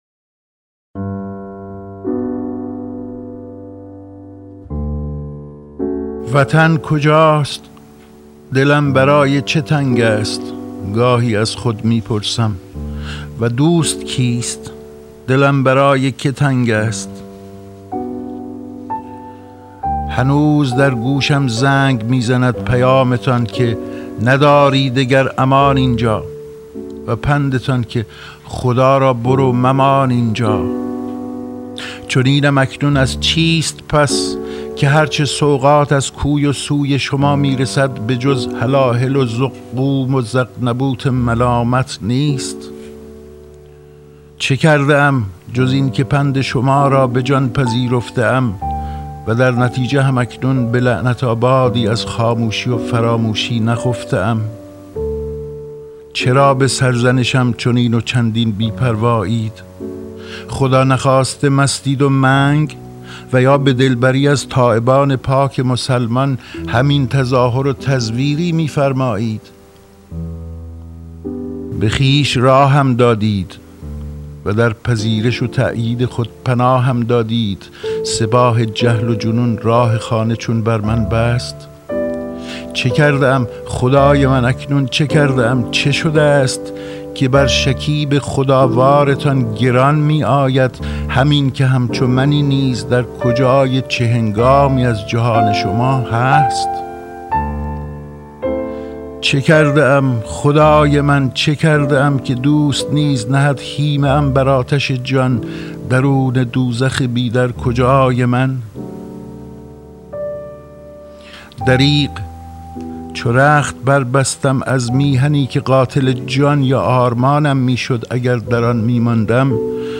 دانلود دکلمه وطن کجاست؟ با صدای اسماعیل خویی با متن دکلمه
گوینده :   [اسماعیل خویی]